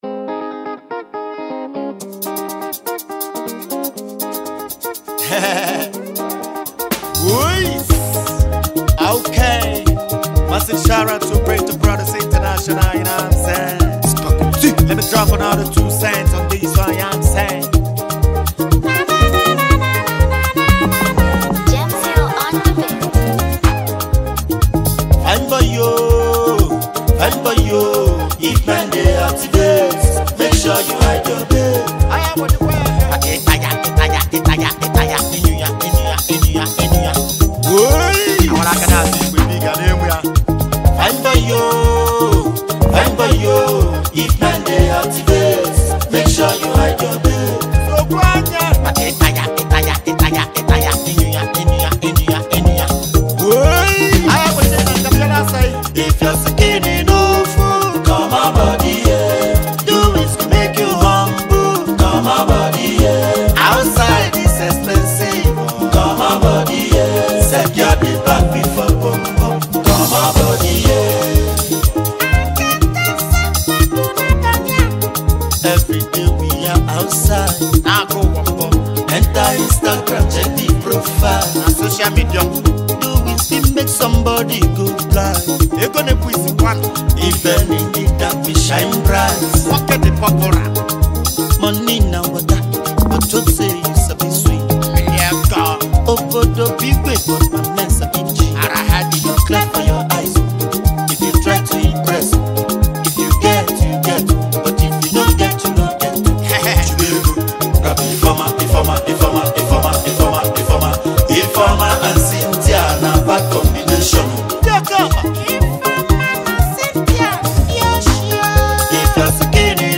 is a vibrant, energetic, and captivating anthem